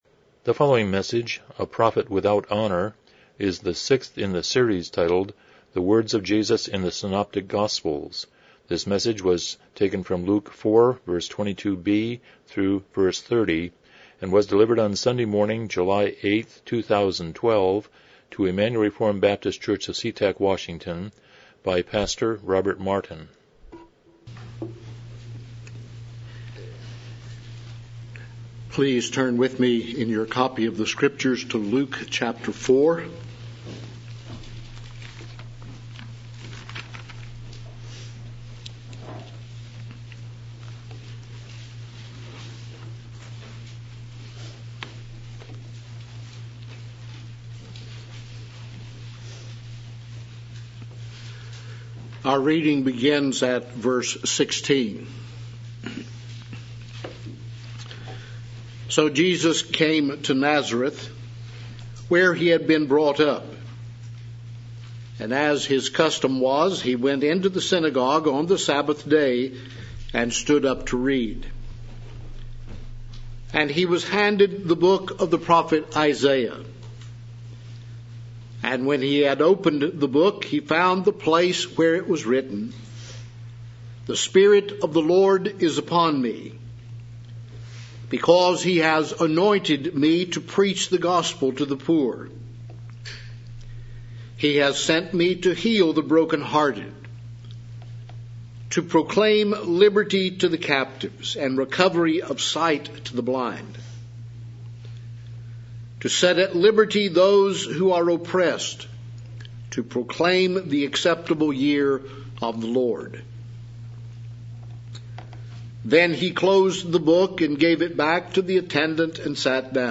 Passage: Luke 4:22-30 Service Type: Morning Worship